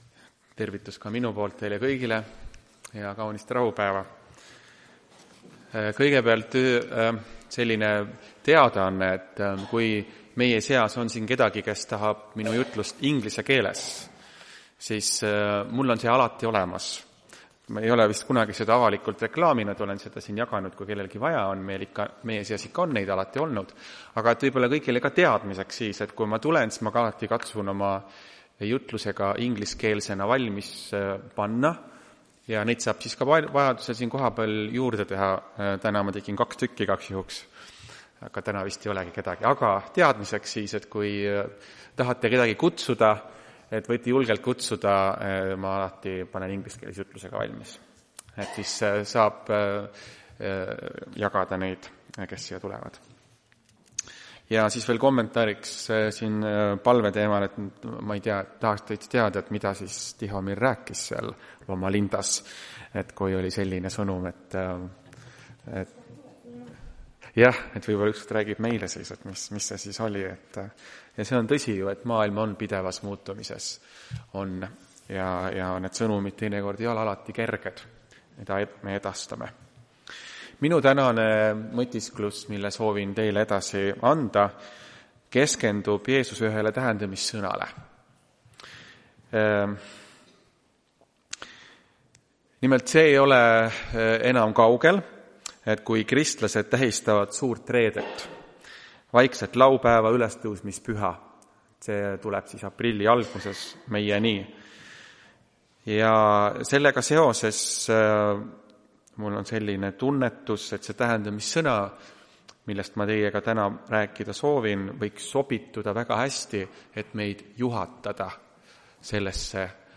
Tartu adventkoguduse 07.03.2026 teenistuse jutluse helisalvestis.